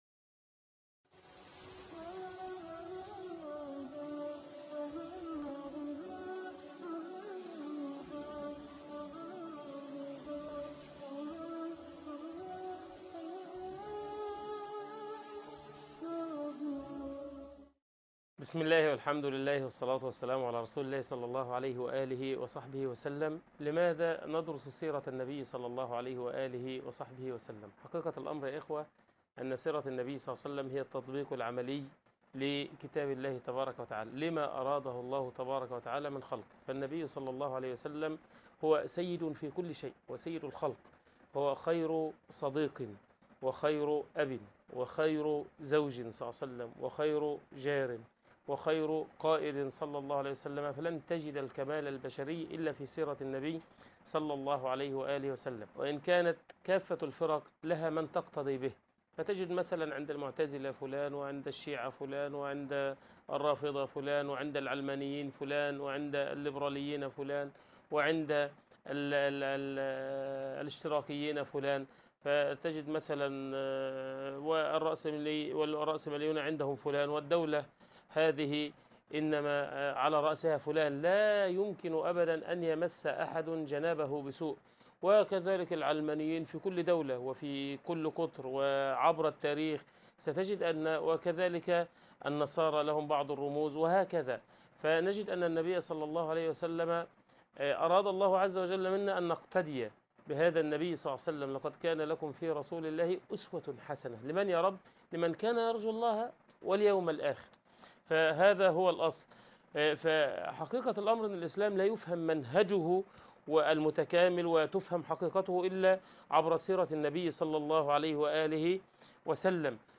السيرة النبوية {المحاضرة الأولى} مقدمات عامة حول السيرة النبوية المطهرة(1)